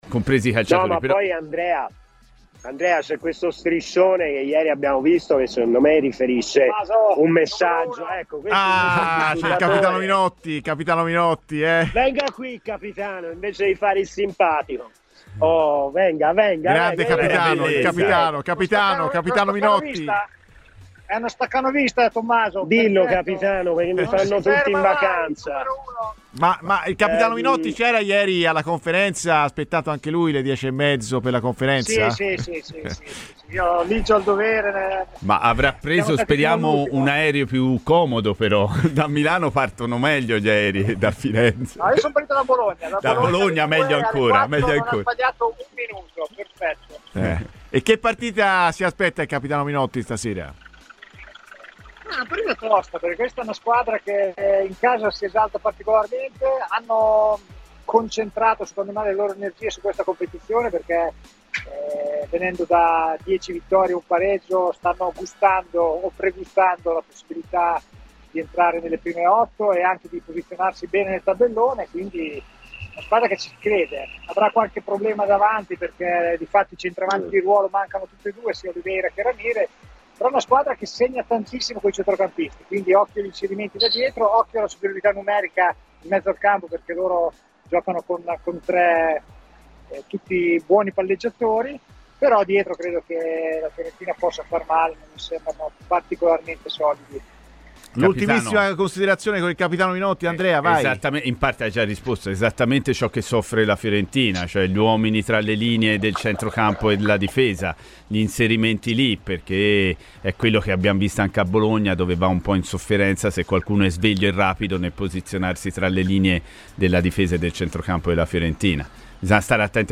L'ex calciatore ed attuale opinionista Sky, Lorenzo Minotti, è intervenuto a Radio FirenzeViola durante "Viola Amore Mio" direttamente da Guimaraes, parlando così della partita di stasera della Fiorentina: "Si tratta di una partita tosta, perché il Vitoria è una squadra che in casa si esalta particolarmente e che sta anche pregustando la possibilità di posizionarsi nelle prime 8 e anche di posizionarsi bene nel tabellone.